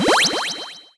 SFX item_get_passive.wav